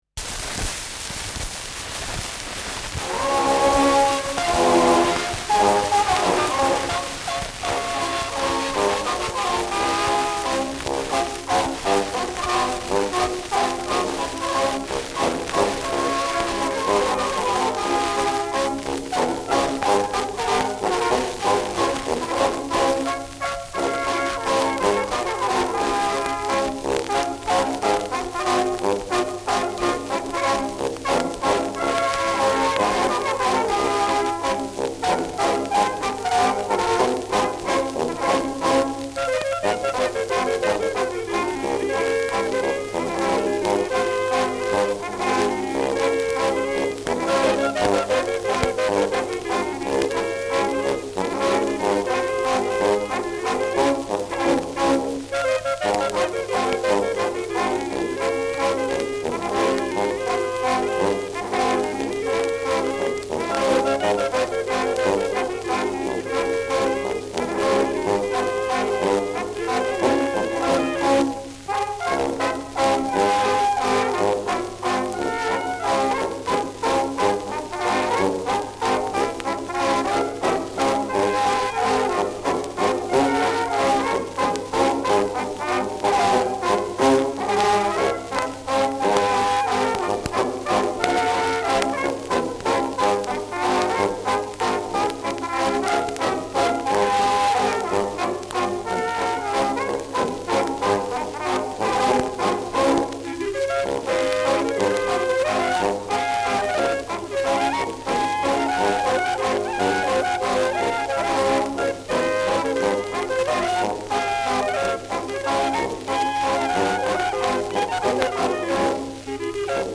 Schellackplatte
präsentes Rauschen : präsentes Knistern : abgespielt : leiert : „Schnarren“ : gelegentliches Knacken
Truderinger, Salzburg (Interpretation)